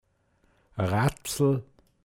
pinzgauer mundart
Rätsel Razl, n.